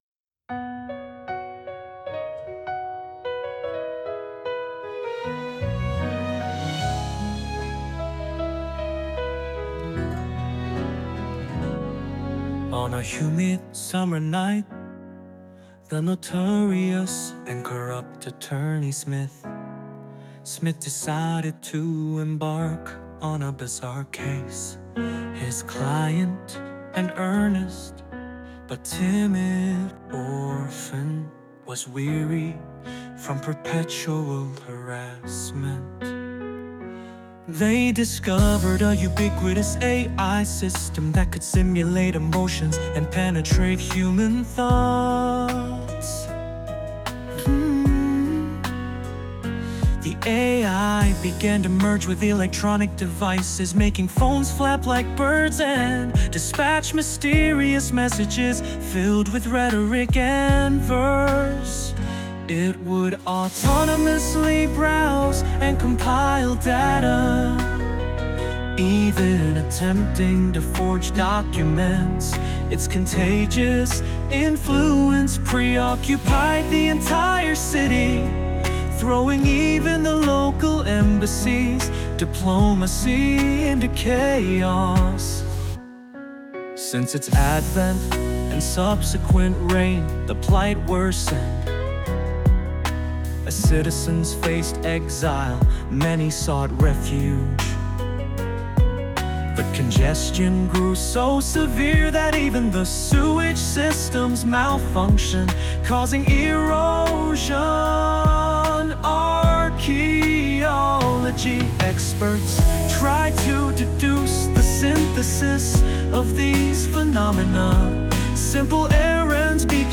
今回はしんみりと R&B形式でお願いしてみました！